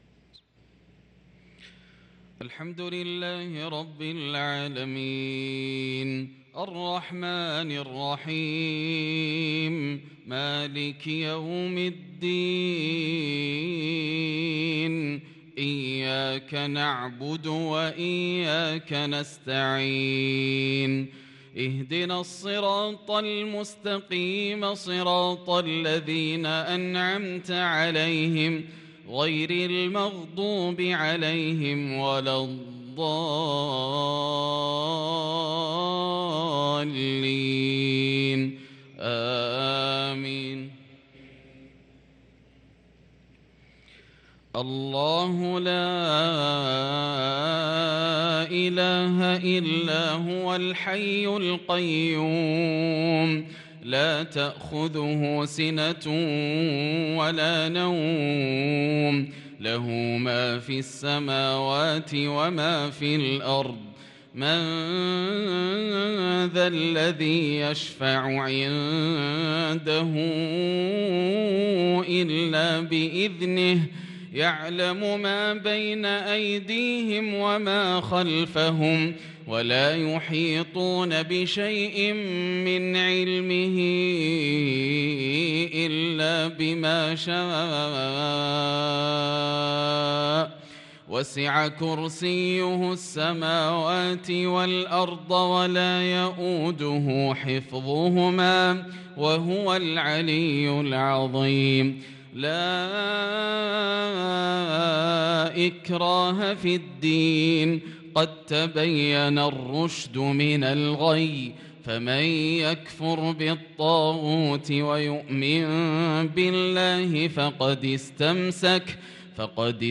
صلاة المغرب للقارئ ياسر الدوسري 25 ذو القعدة 1443 هـ
تِلَاوَات الْحَرَمَيْن .